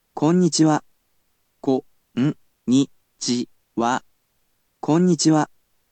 You’ll be able to hear an organic voice in another resource, but for now, he’ll definitely help you learn whilst at least hearing the words and learning to pronounce them.